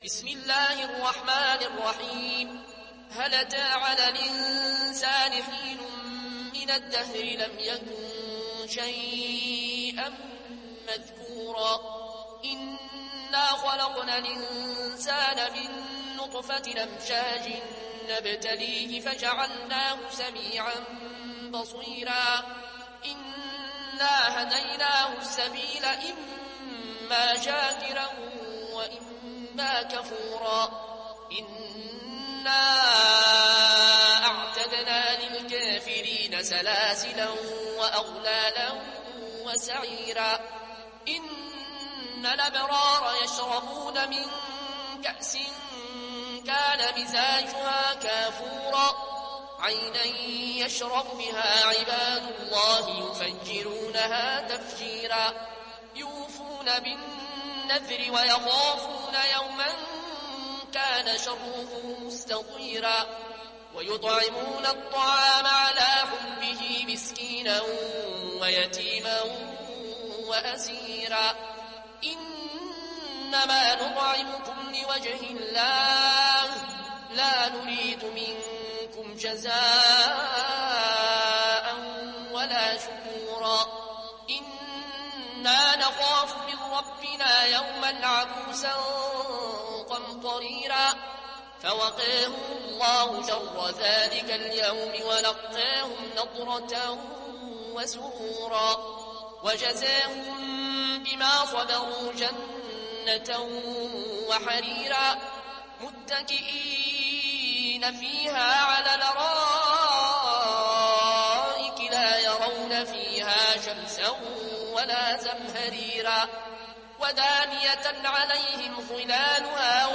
(Riwayat Warsh)